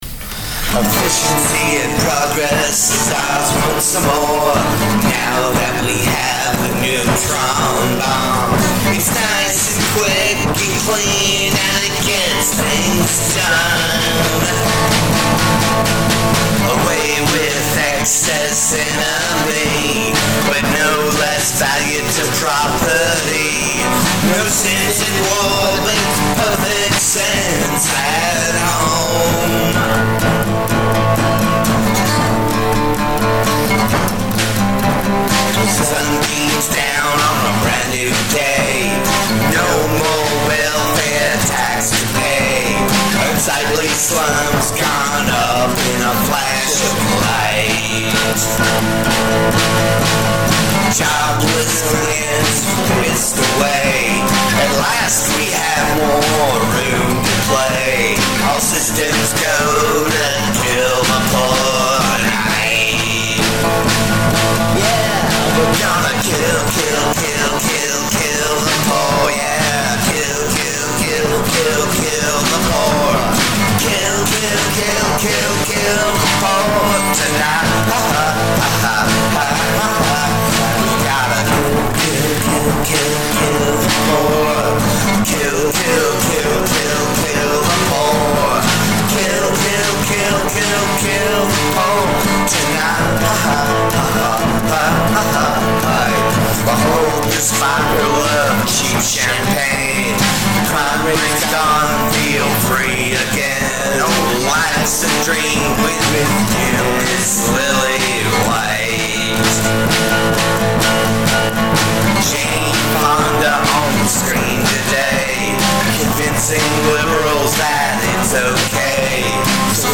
old school punk tune